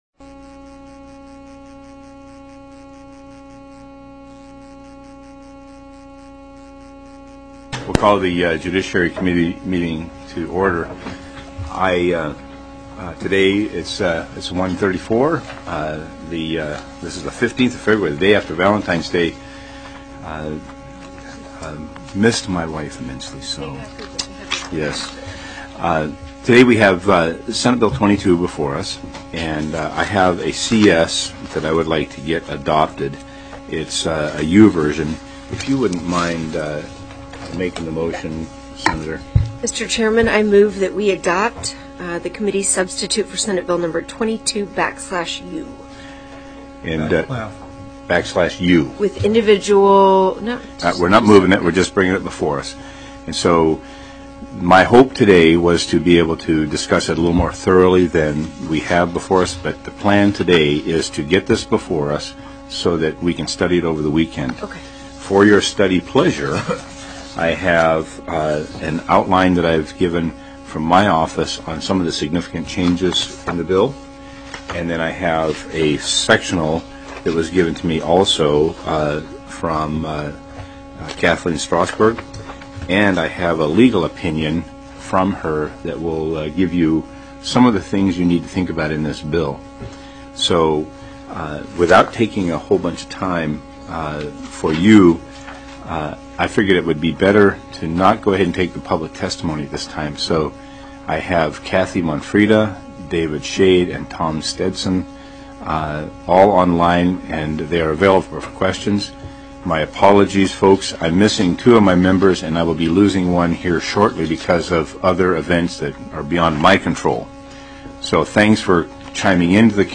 Legislature(2013 - 2014)BELTZ 105 (TSBldg)
02/15/2013 01:30 PM Senate JUDICIARY
+ teleconferenced